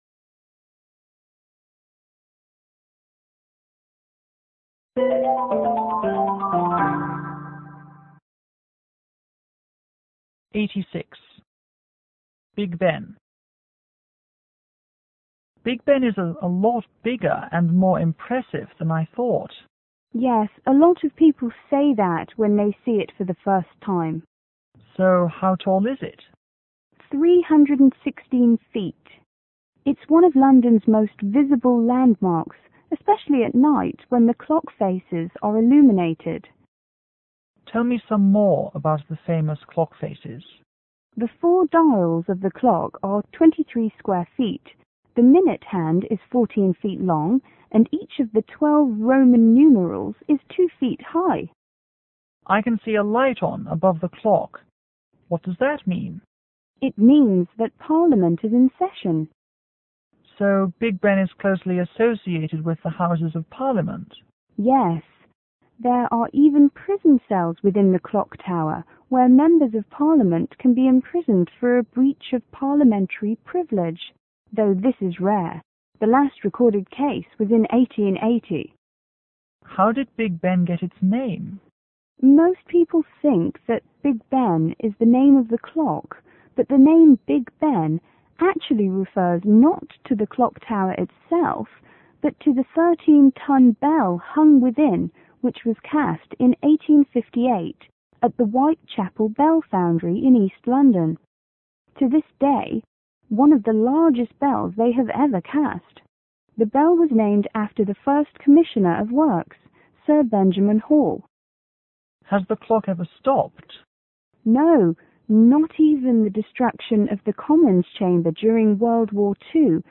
C: Chinese student      G: Guide